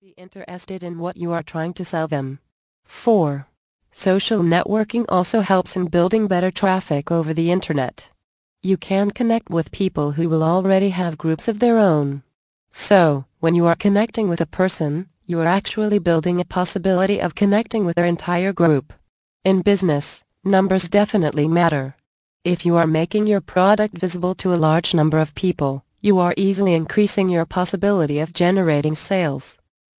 Indispensable Almanac of Internet Marketing mp3 Audio Book 4